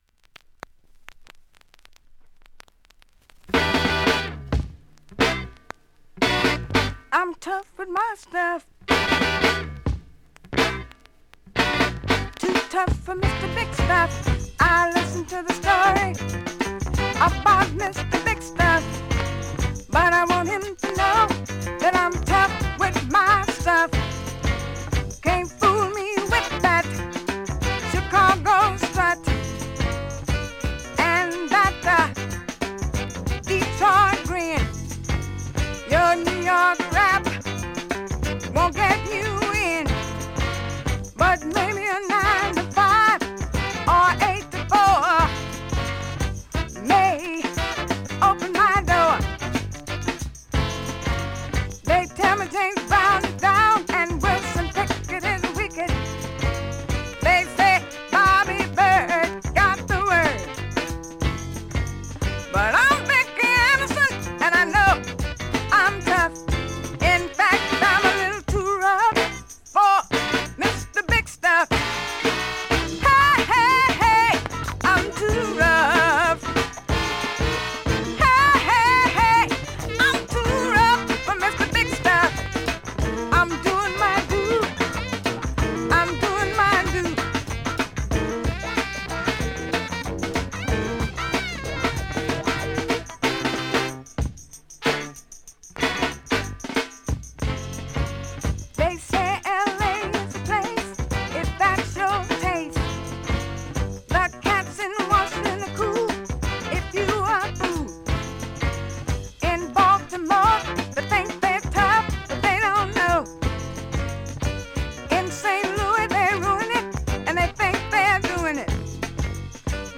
◆USA盤 オリジナル 7"Single 45 RPM
現物の試聴（両面すべて録音時間６分）できます。